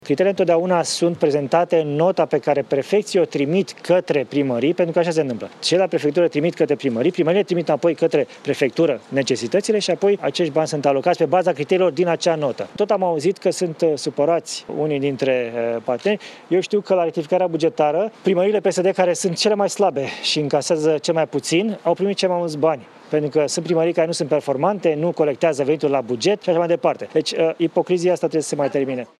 Premierul demis Florin Cîțu vine cu explicații după alocarea unui milliard de lei din fondul aflat la dispoziția sa mai multor autorități locale, peste 2.700.